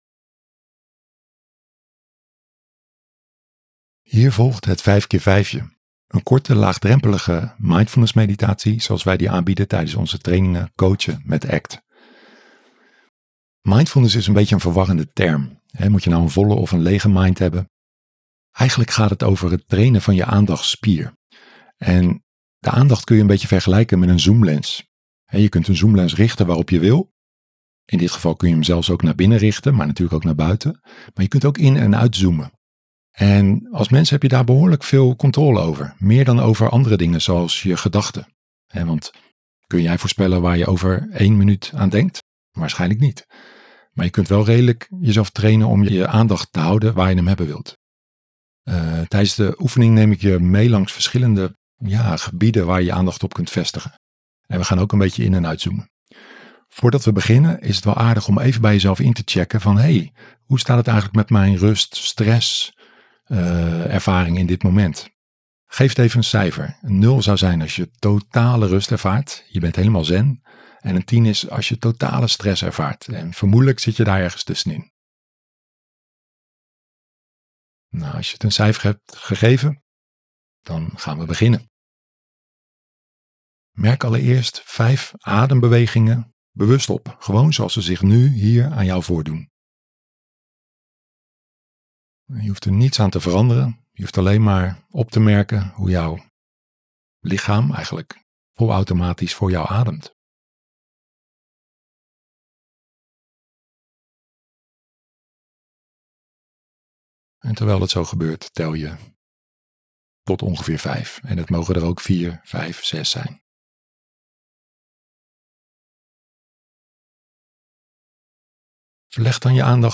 #78 Meditatie: Het 5x5 je.